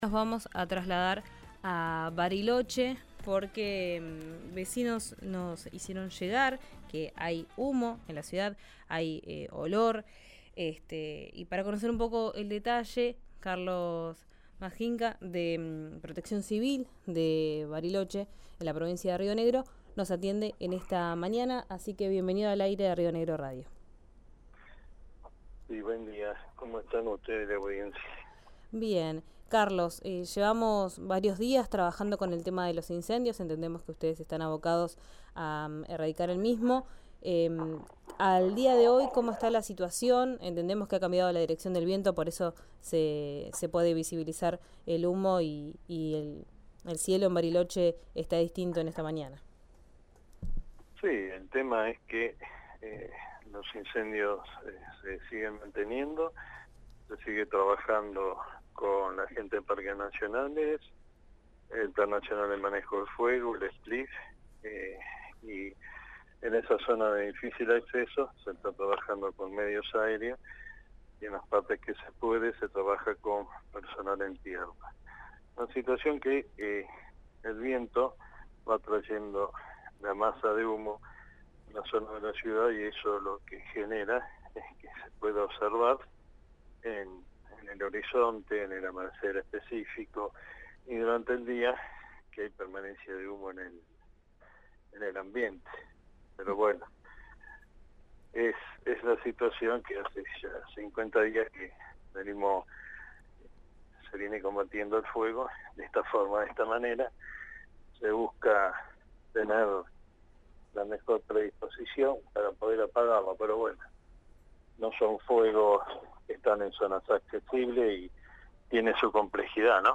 Escuchá a Carlos Madjinca, secretario de Protección Civil de Bariloche, en RÍO NEGRO RADIO:
«Son fuegos que no están en zonas accesibles», indicó el funcionario en diálogo con Vos en Verano.